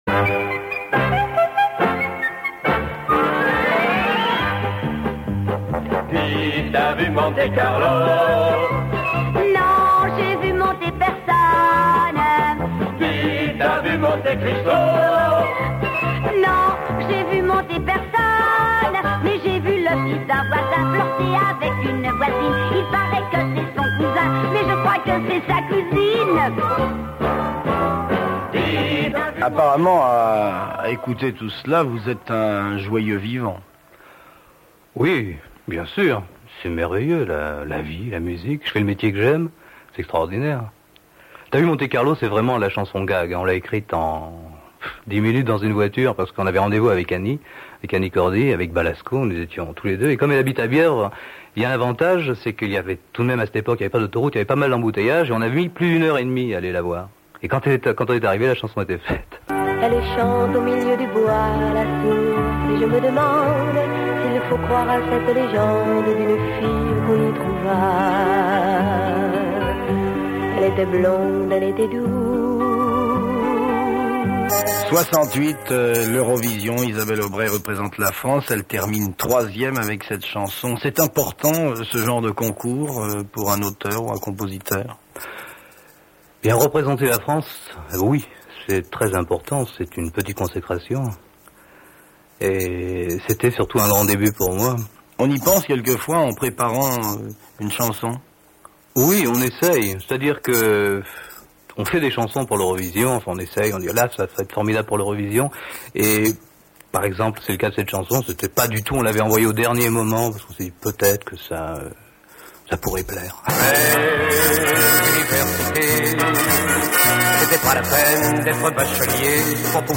Interview
petits problèmes de son